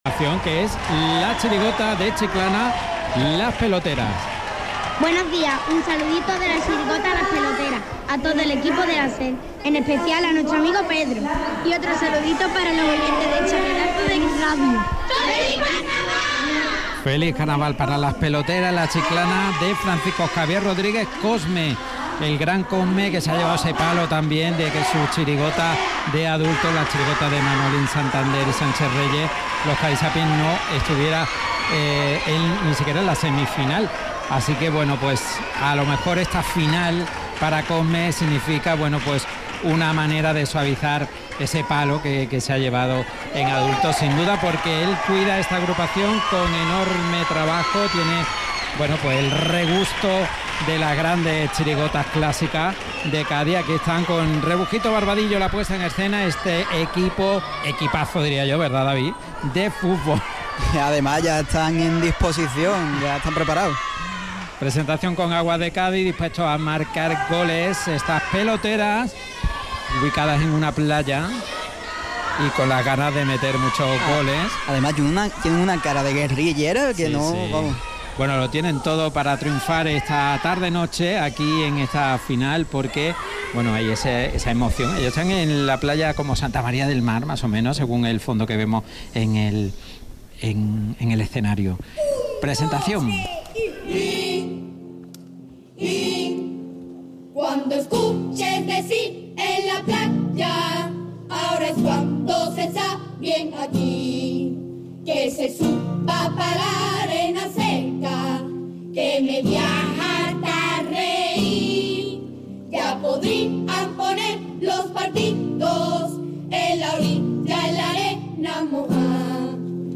Audio de La cantera del Carnaval de Cádiz en Podium Podcast
Chirigota Infantil - Las peloteras Final